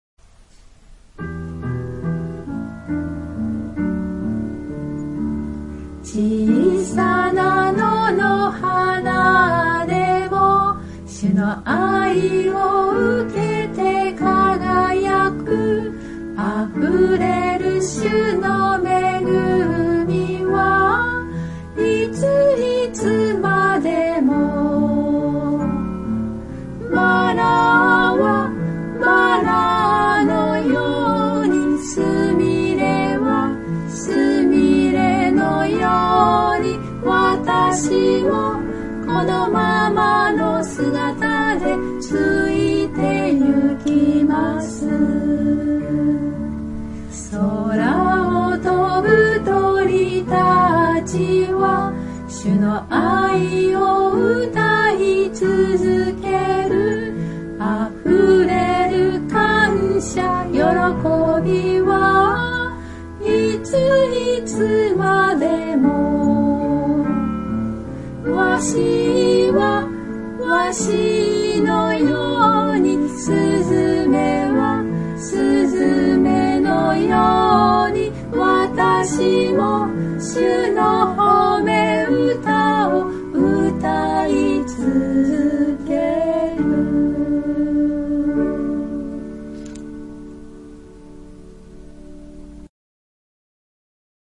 God bless you）より 唄